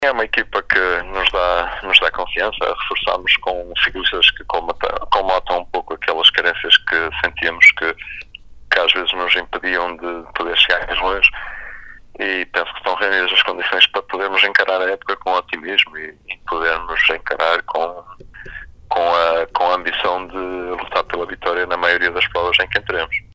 Em entrevista à Sintonia